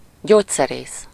Ääntäminen
Ääntäminen Tuntematon aksentti: IPA: /ˈɟoːcsɛreːs/ IPA: ˈɟoːt͡sːɛreːs/ Haettu sana löytyi näillä lähdekielillä: unkari Käännös Konteksti Substantiivit 1. pharmacist farmasia Luokat Terveydenhuoltoalan ammatit